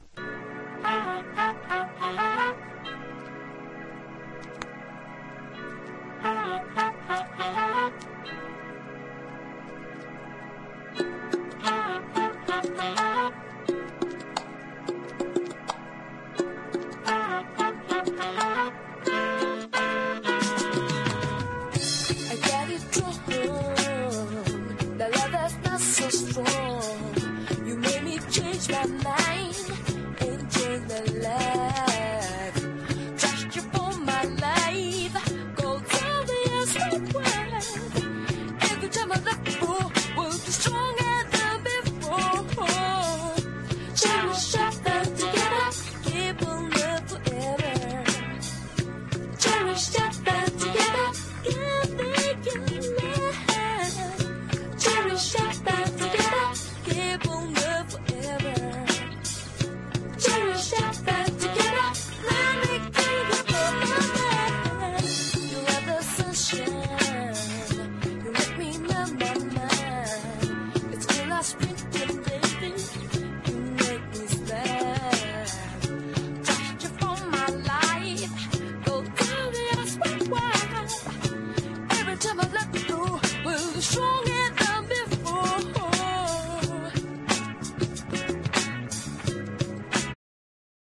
異様なほどの歌唱力に圧倒されます！